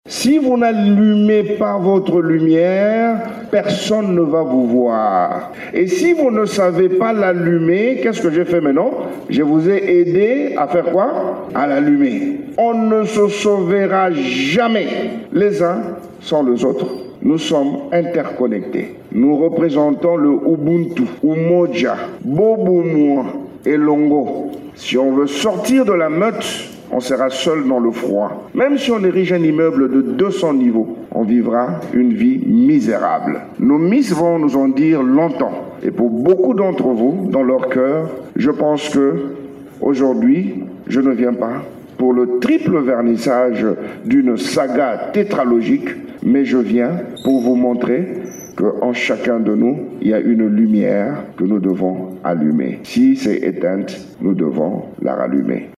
Il a lancé cette invitation, vendredi 20 février, lors de la présentation de ses trois ouvrages intitulés : « Ifoku » tome 1, « Mukole » tome 2 et « Maliza » tome 3, lors d’une cérémonie organisée au Centre Wallonie-Bruxelles de Kinshasa.